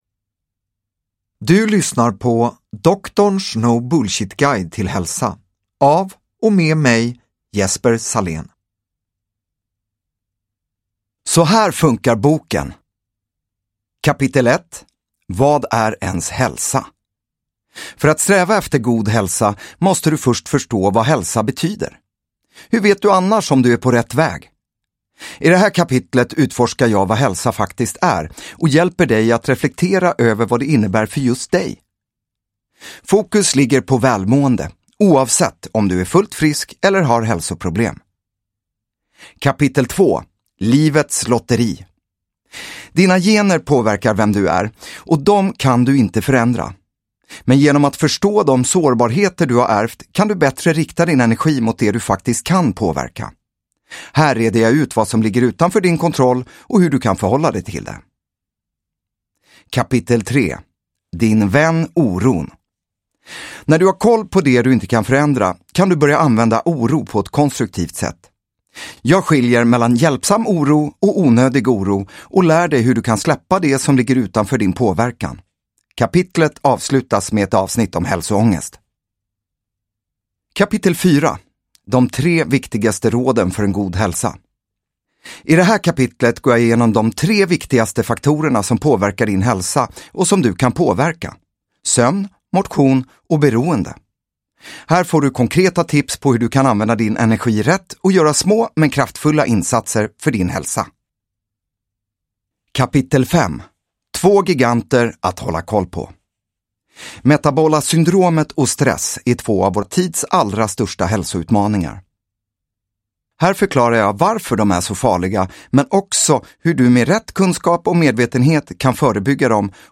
Doktorns no bullshit-guide till hälsa – Ljudbok